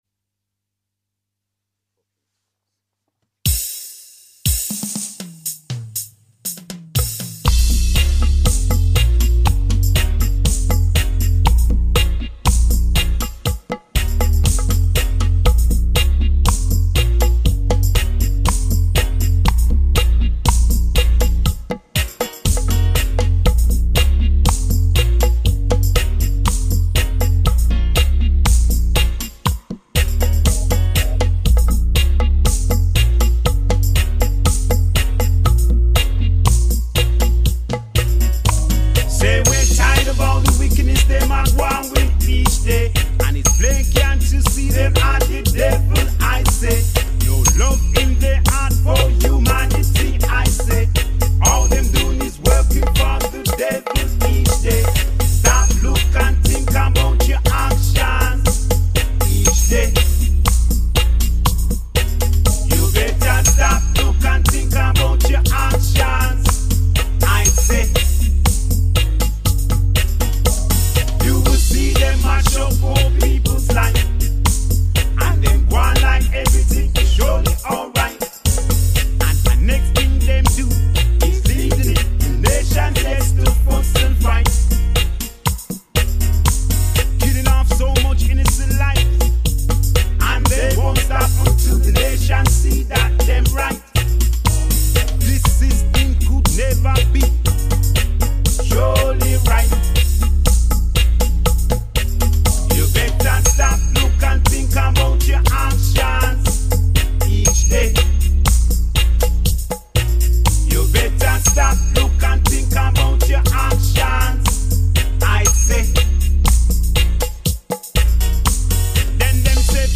vocals and akete recorded at hall place studio uk